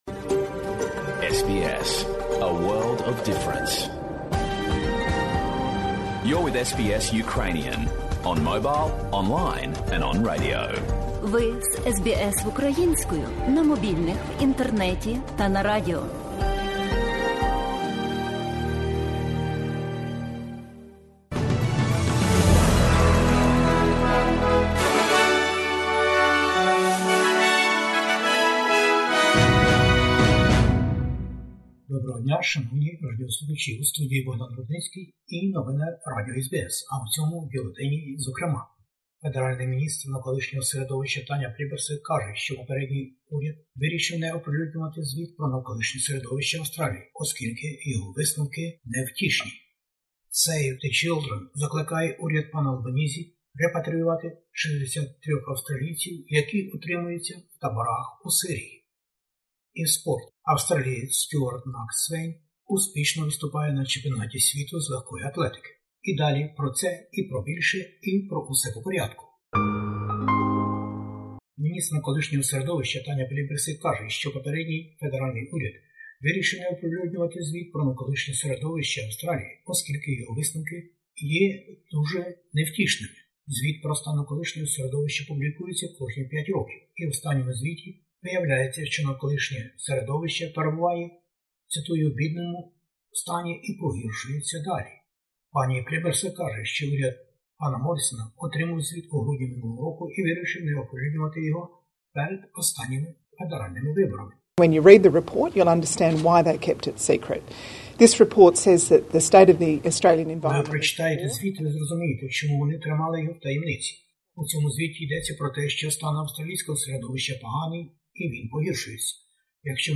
Бюлетень SBS новин українською мовою. Навколишнє серeдовище крізь призму звіту про його стан - Федеральний уряд. COVID-19 - новий варіант вірусу Omicron виявляється більш складнішим щодо імунітету, ніж передбачалося в Австралії.